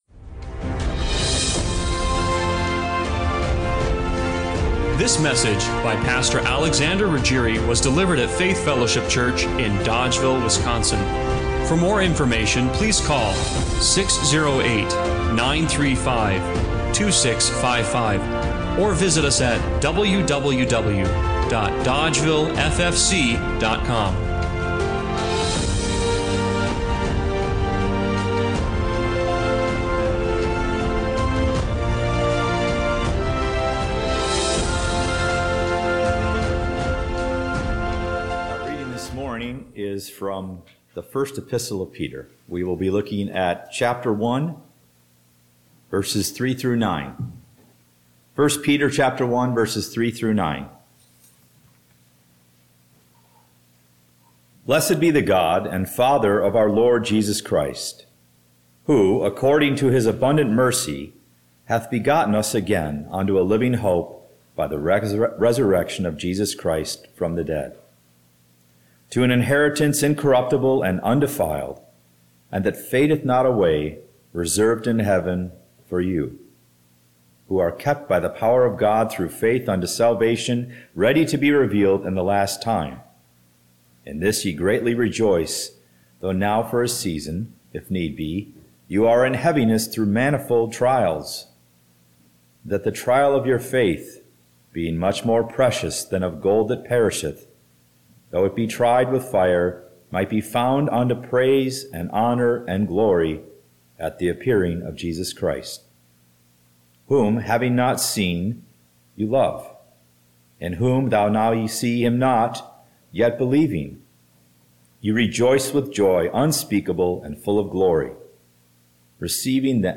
1 Peter 1:3-9 Service Type: Sunday Morning Worship Have you ever tried to describe color to the blind